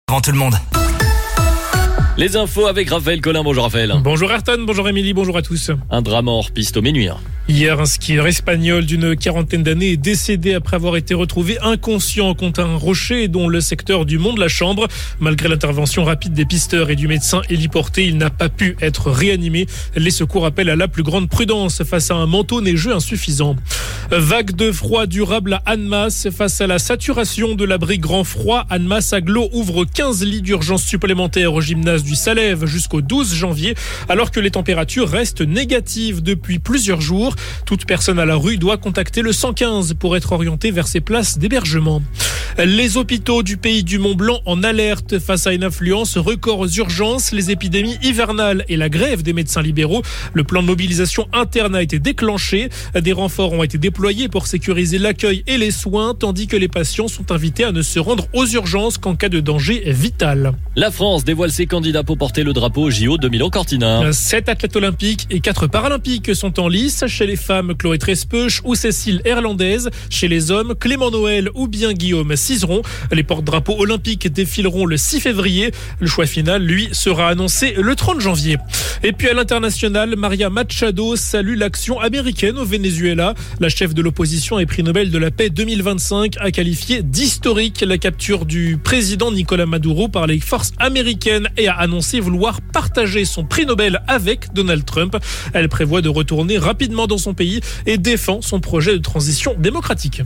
Votre flash info - votre journal d'information sur La Radio Plus